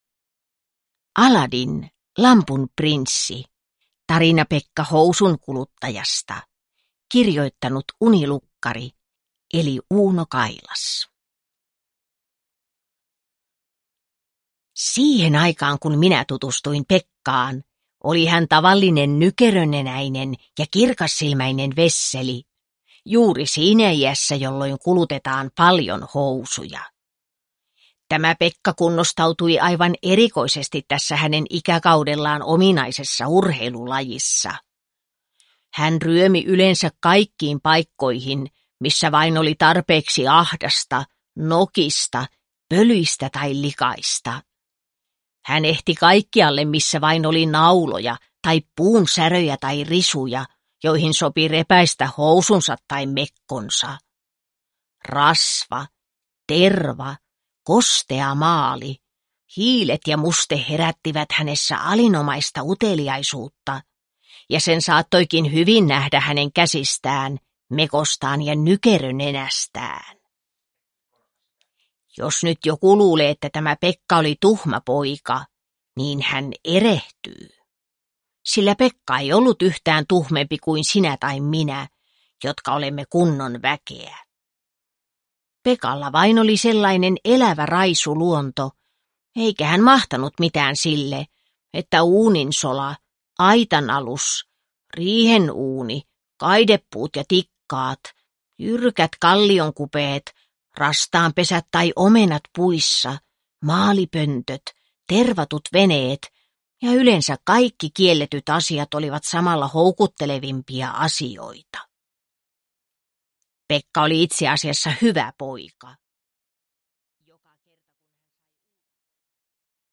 Aladdin, lampun prinssi. Tarina Pekka Housunkuluttajasta (ljudbok) av Uuno Kailas